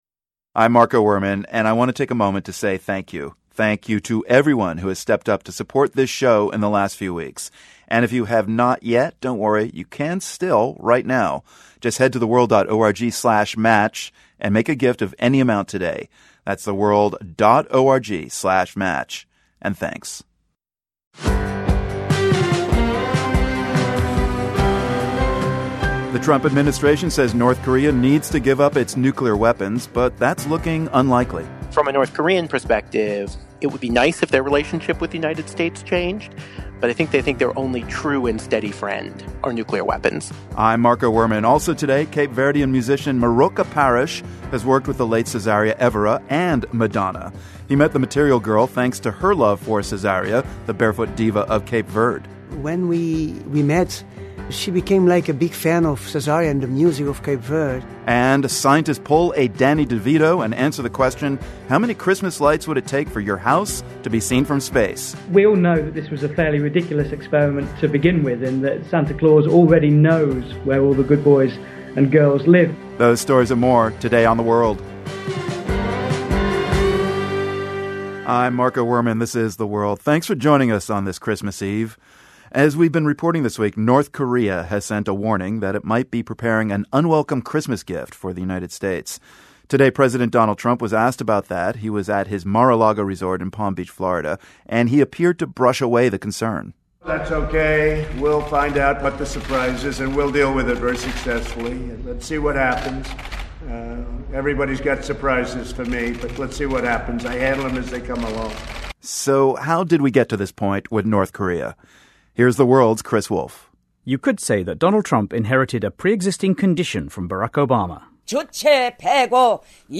The World is a public media news program that relies on the support of listeners like you.